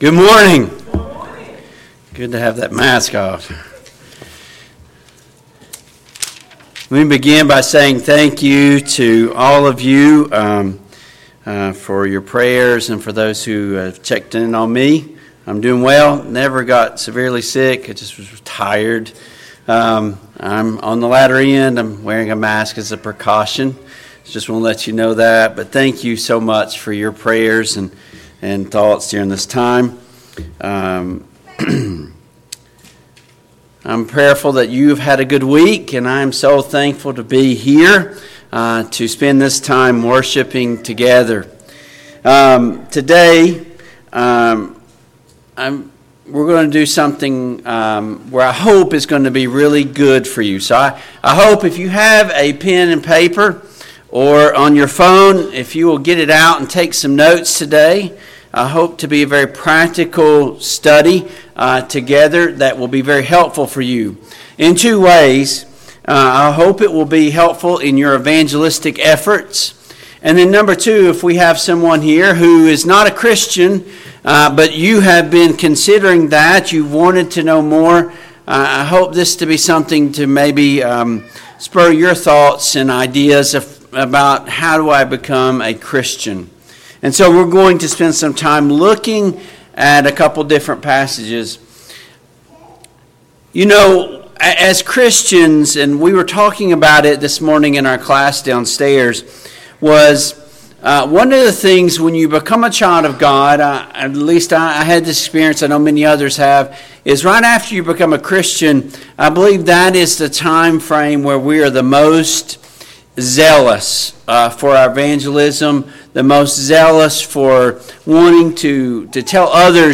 Passage: Acts 7:58, Acts 8:1-4, Acts 9:1-19, Acts 22:6-16, Acts 26:12-23 Service Type: AM Worship Download Files Notes Topics